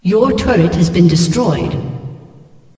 yourturret_destroyed.mp3